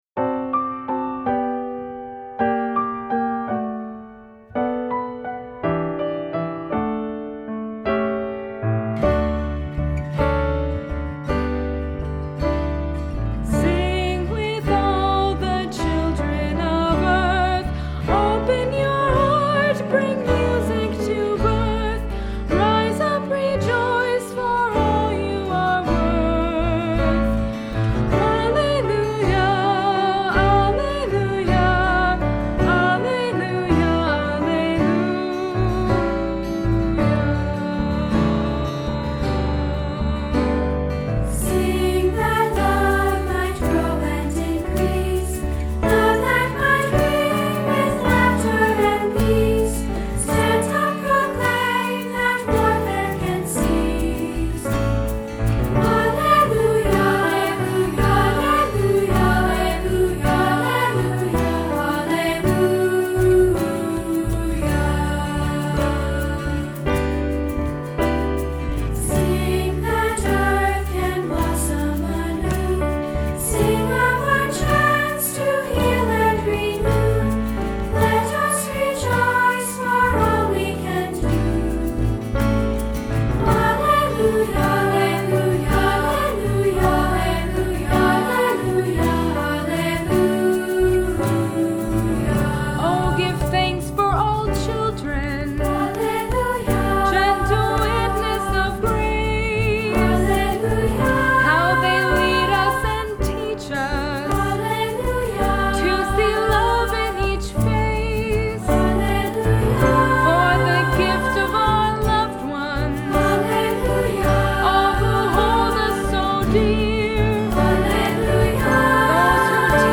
Music Category:      Christian